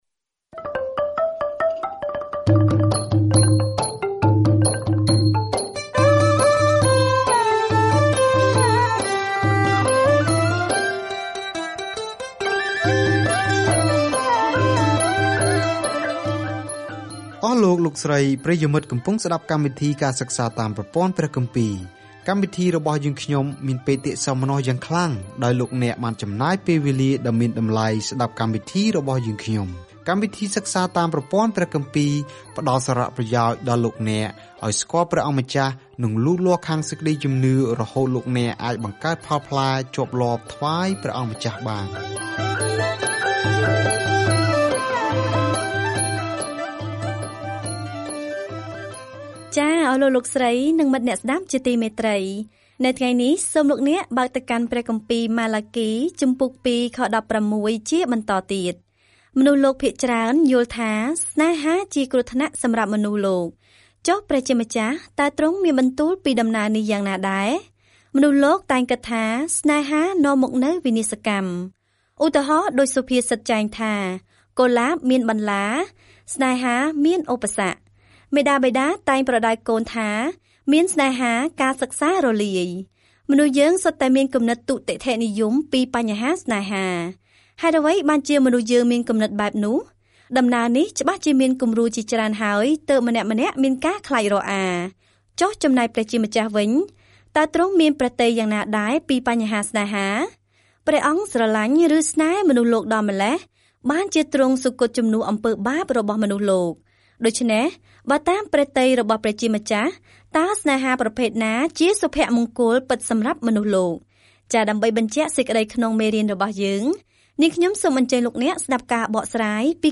ម៉ាឡាគីរំឭកជនជាតិអ៊ីស្រាអែលដែលផ្តាច់ទំនាក់ទំនងថាគាត់មានសារពីព្រះមុនពេលពួកគេស៊ូទ្រាំនឹងភាពស្ងៀមស្ងាត់ដ៏យូរ - ដែលនឹងបញ្ចប់នៅពេលដែលព្រះយេស៊ូវគ្រីស្ទចូលដល់ឆាក។ ការធ្វើដំណើរជារៀងរាល់ថ្ងៃតាមរយៈម៉ាឡាគី នៅពេលអ្នកស្តាប់ការសិក្សាជាសំឡេង ហើយអានខគម្ពីរដែលជ្រើសរើសពីព្រះបន្ទូលរបស់ព្រះ។